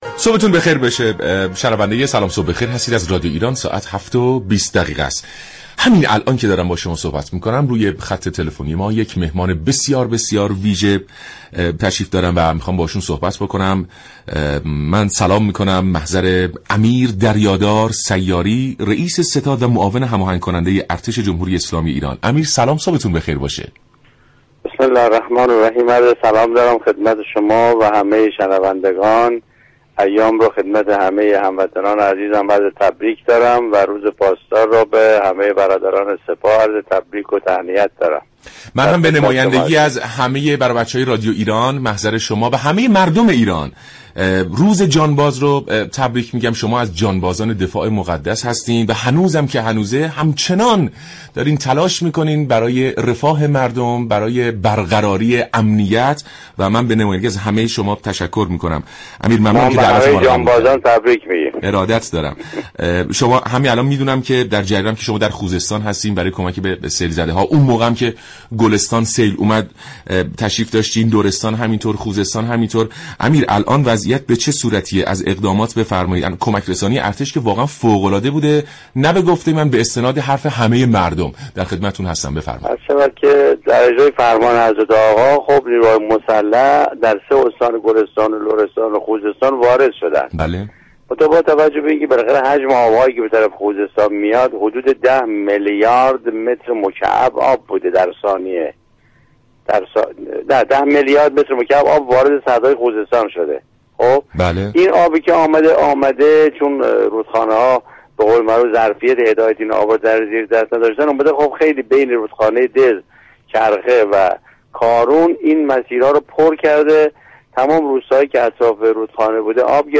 امیردریادار حبیب الله سیاری معاون هماهنگ‌كننده ارتش جمهوری اسلامی ایران در برنامه سلام صبح بخیر رادیو ایران گفت : مردم اخبار را از ستاد بحران گوش كنند و به شایعات توجه نكنند و با نیروهای امدادگر همكاری كنند